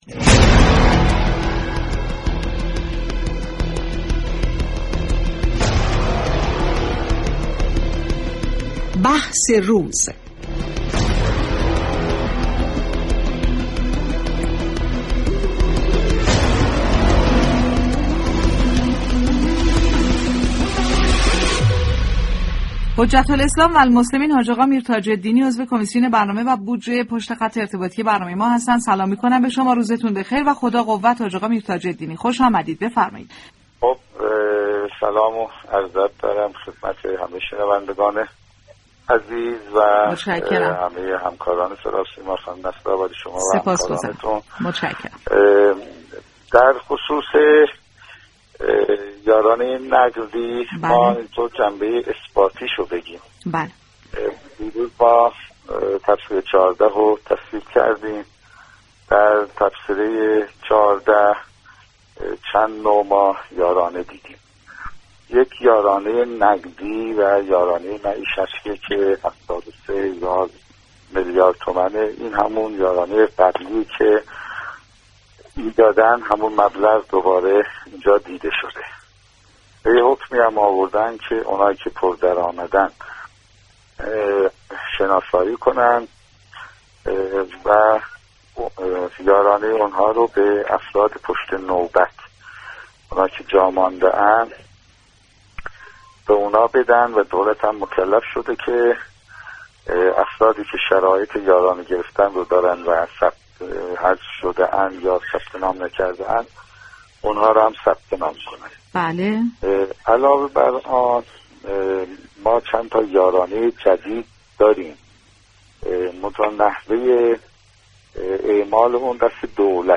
به گزارش پایگاه اطلاع رسانی رادیو تهران، محمدرضا میرتاج‌الدینی نایب رئیس كمیسیون برنامه و بودجه مجلس شورای اسلامی در گفتگو با بازار تهران رادیو تهران تصریح كرد: طبق مصوبه كمیسیون تلفیق، میزان یارانه نقدی و معیشتی رقم 73 هزار میلیارد تومان است و همان مبلغ 45 هزار و 500 تومانی سابق در نظر گرفته شده است و در حكمی قرار است كسانی كه پر درآمد هستند شناسایی و یارانه آنها به بازماندگان از دریافت یارانه پرداخت شود.